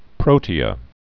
(prōtē-ə)